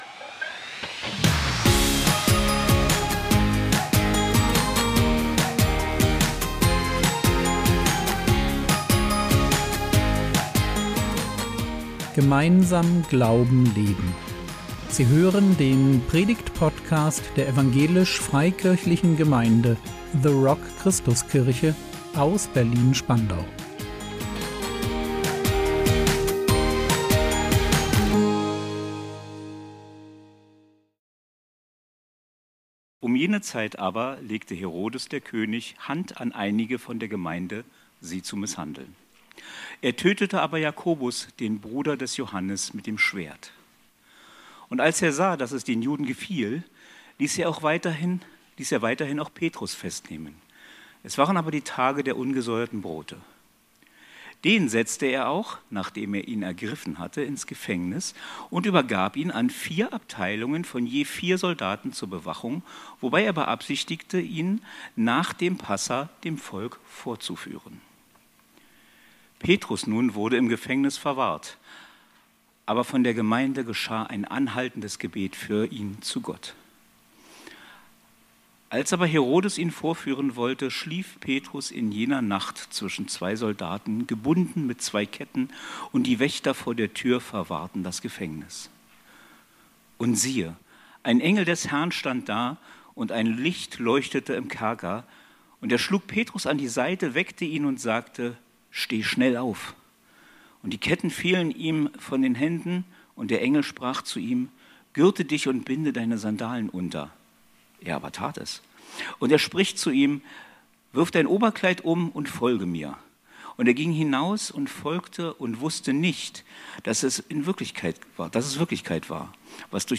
König vs. Kyrios | 01.03.2026 ~ Predigt Podcast der EFG The Rock Christuskirche Berlin Podcast